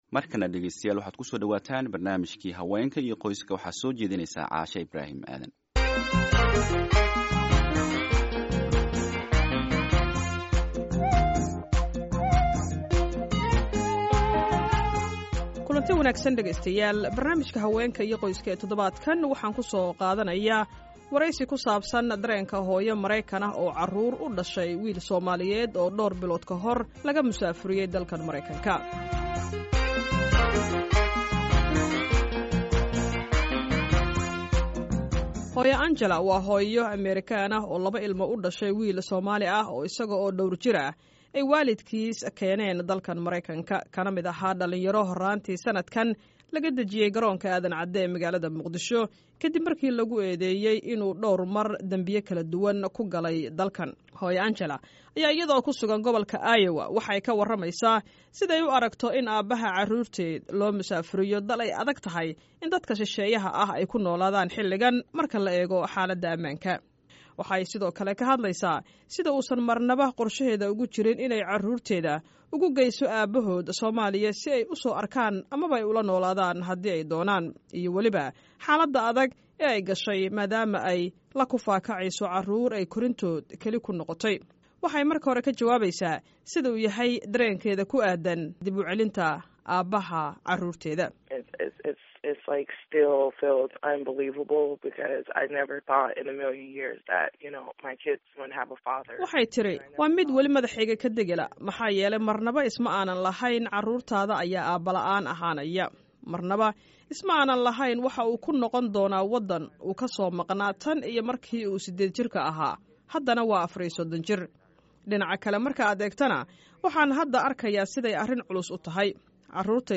Barnaamika Haweenka iyo qoyska ee toddobaadka waxaan ku soo qaadaneynaa wareysi ku saabsan dareenka hooyo maraykan ah oo carruur u dhashay wiil Soomaaliyeed oo dhowr bilood ka hor laga musaafiryay dalka Maraykanka.